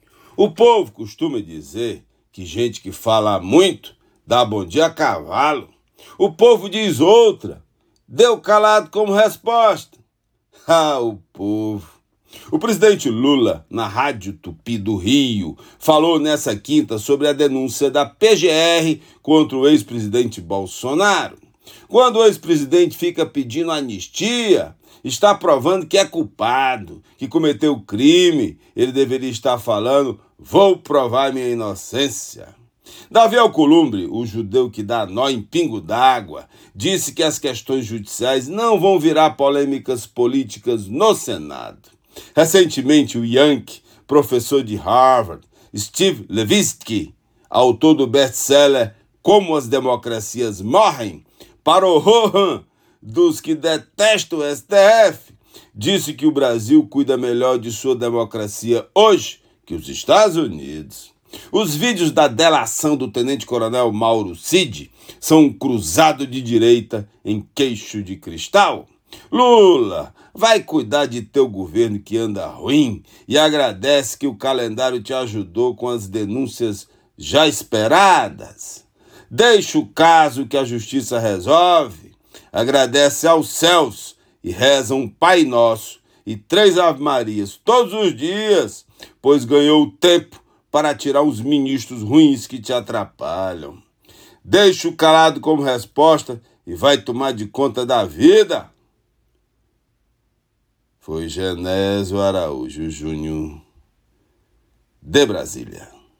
Comentário